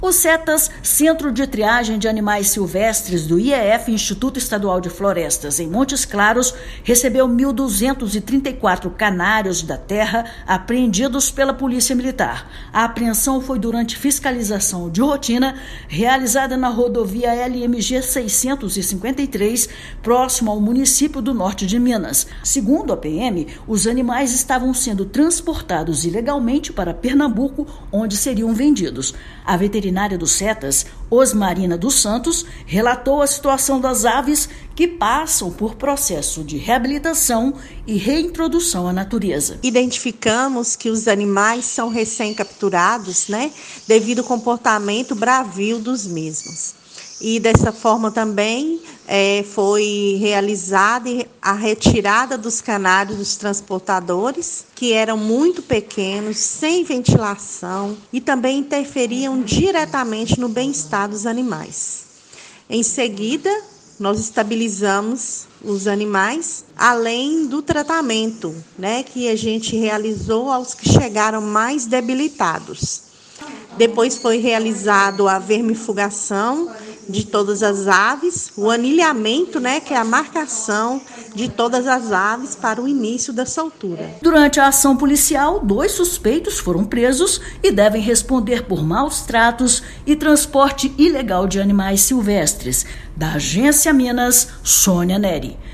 O Centro de Triagem de Animais Silvestres (Cetas), do Instituto Estadual de Florestas (IEF), em Montes Claros, recebeu 1.234 canários da terra apreendidos pela Polícia Militar. Ouça matéria de rádio.